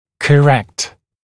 [kə’rekt][кэ’рэкт]правильный; исправлятьcorrected исправленный, скорректированный